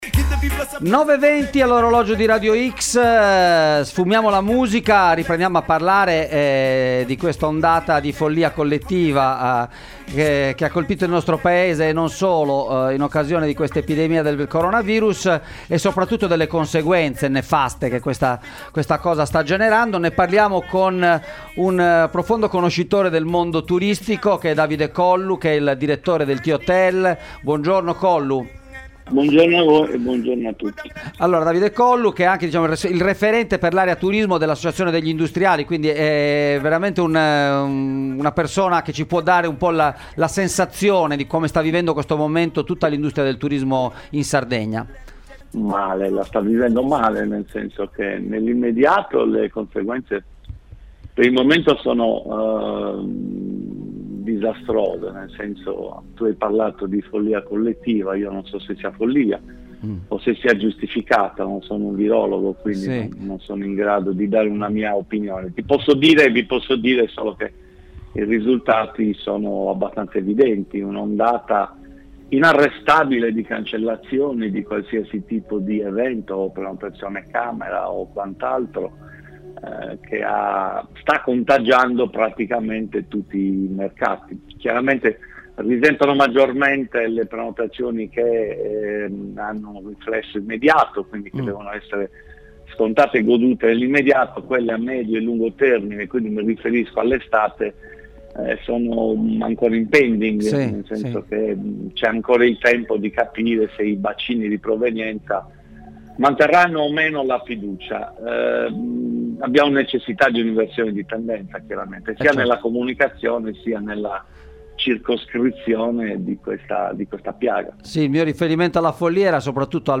Psicosi coronavirus: conseguenze disastrose per il turismo nell’isola – intervista